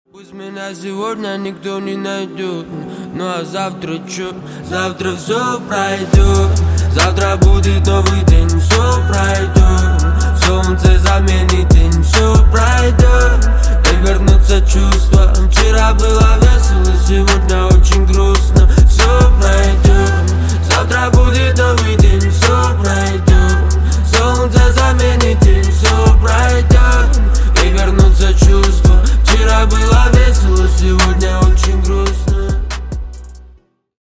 Грустные Рингтоны
Рэп Хип-Хоп Рингтоны